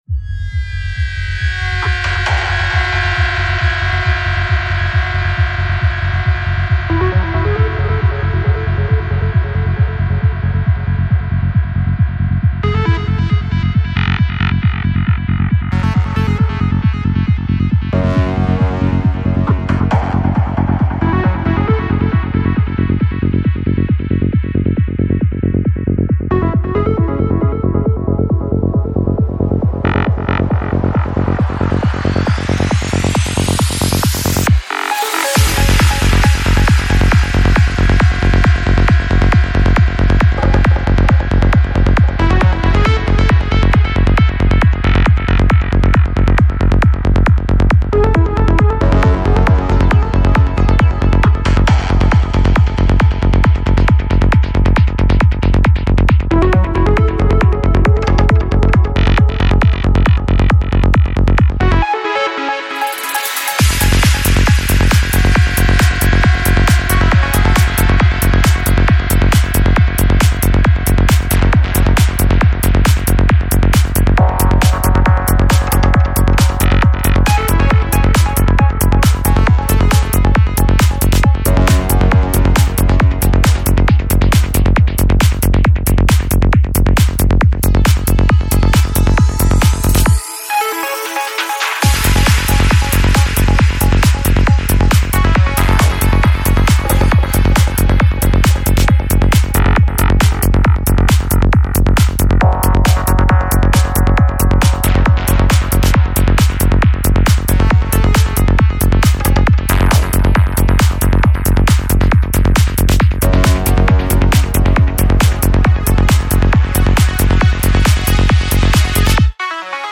Жанр: Trance
Psy-Trance